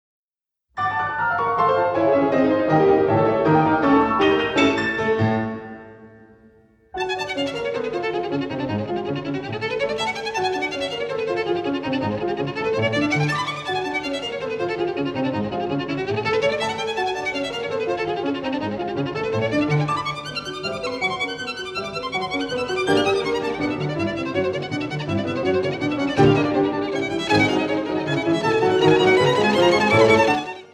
Violin
in concert and in studio